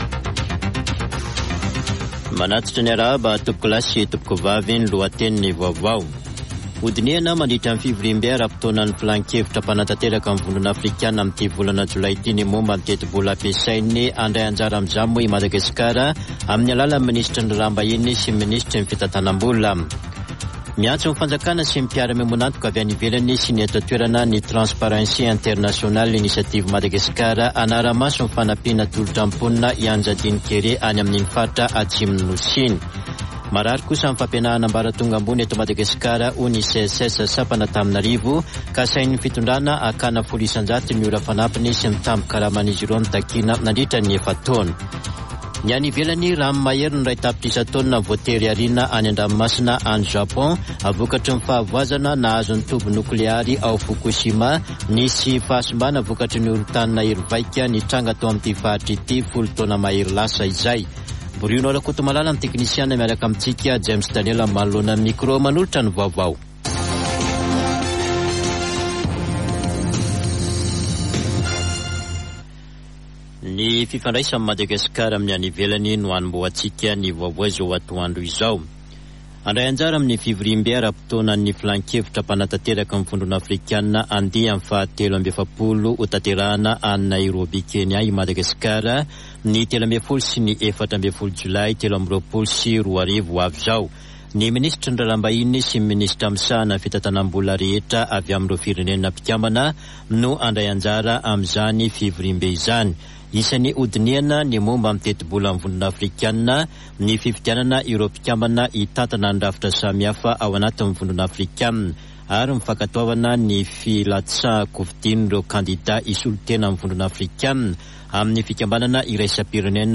[Vaovao antoandro] Alakamisy 6 jolay 2023